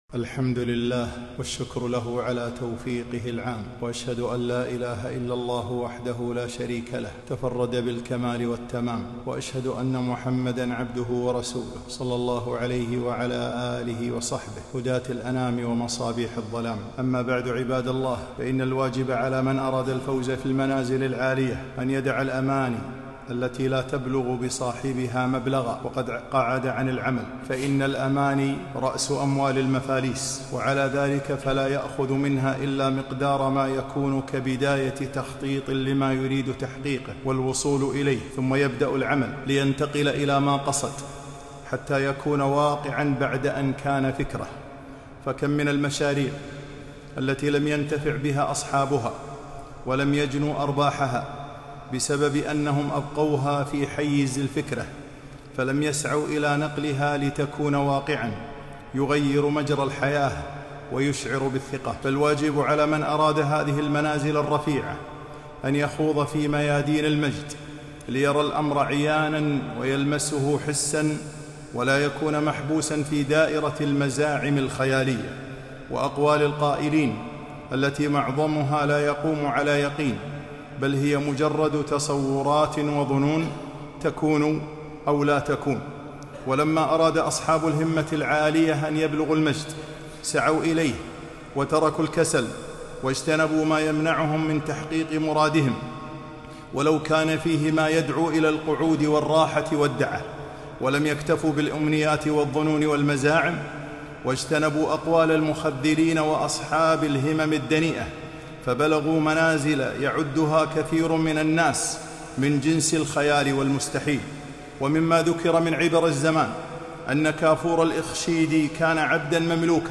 خطبة - الصعود نحو القمة